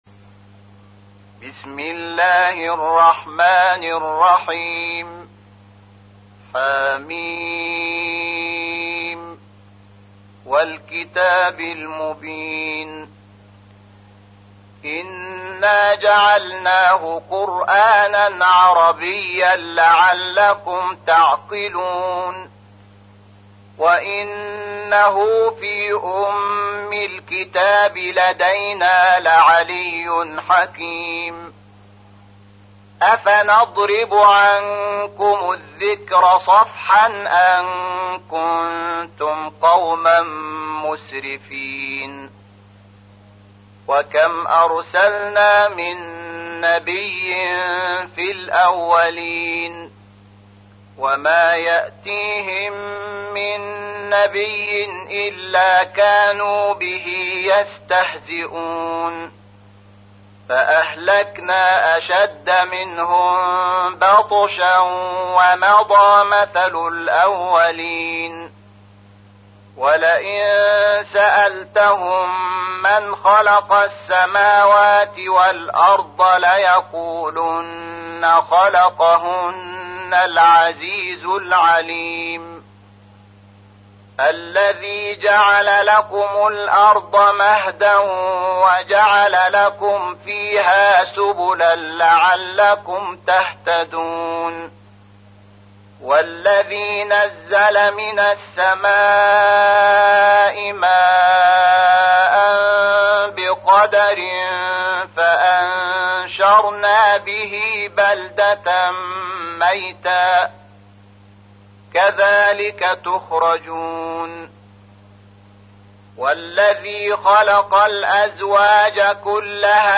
تحميل : 43. سورة الزخرف / القارئ شحات محمد انور / القرآن الكريم / موقع يا حسين